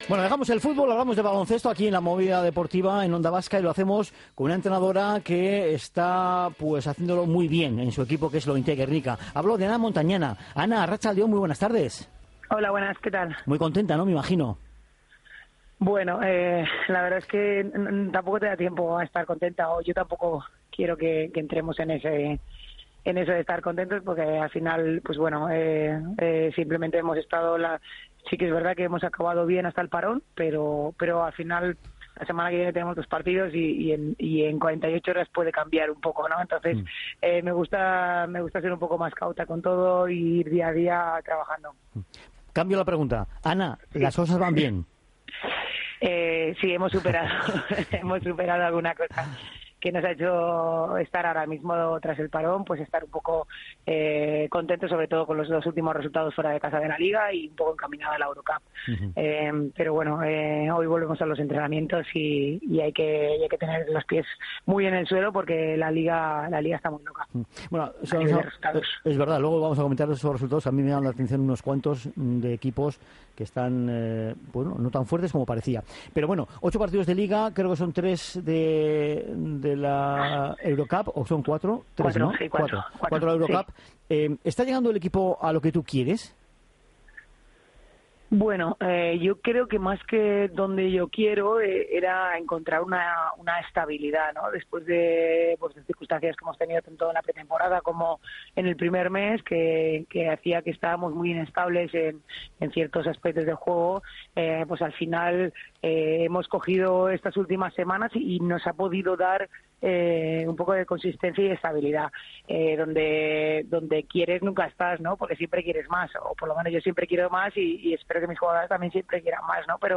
La entrenadora de Lointek Gernika, Anna Montañana, se ha pasado por los micrófonos de Onda Vasca para analizar el momento actual del equipo aprovechando el parón en la Liga Femenina por las ventanas FIBA.